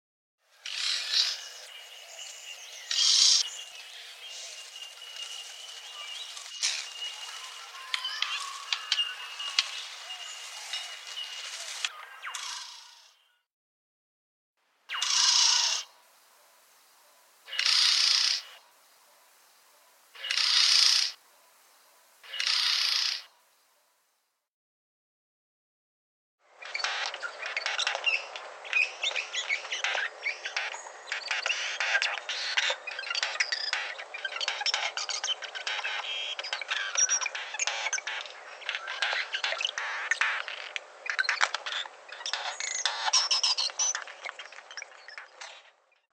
Regent Bowerbird
Songs & Calls
regent-bowerbird-web.mp3